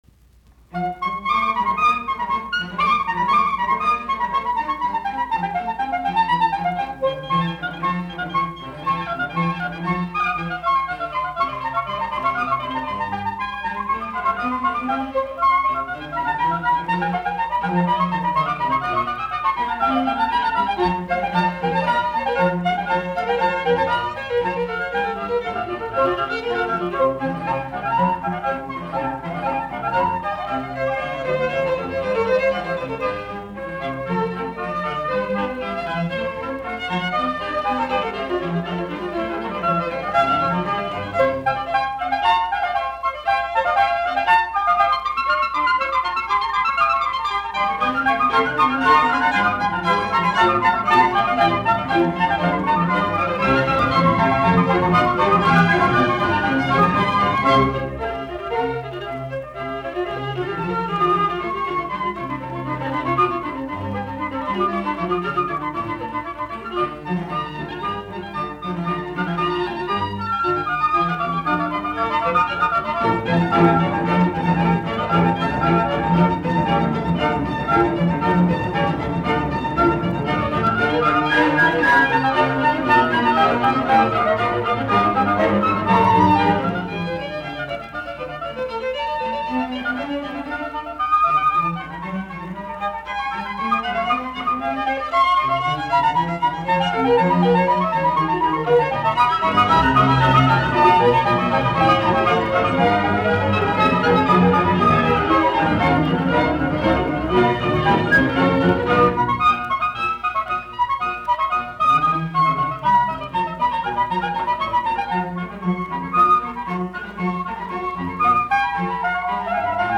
BWV1047, F-duuri